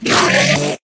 scream4.ogg